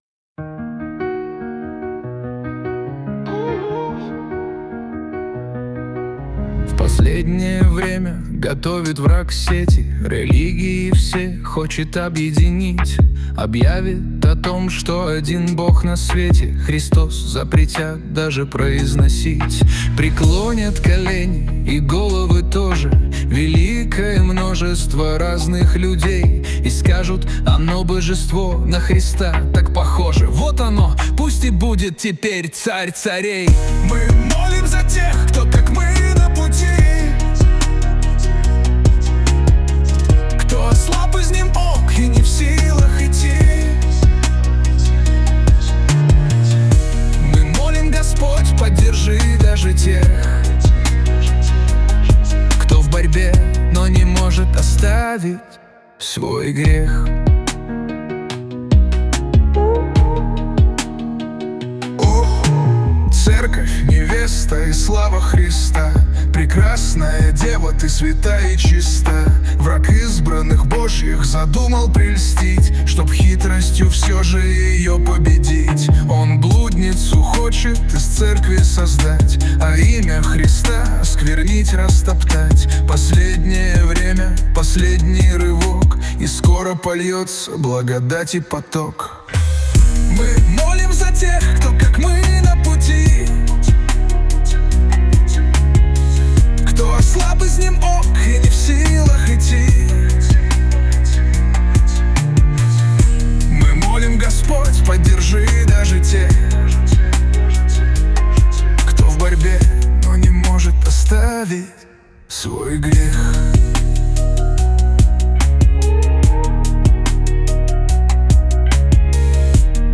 песня ai
256 просмотров 1042 прослушивания 83 скачивания BPM: 73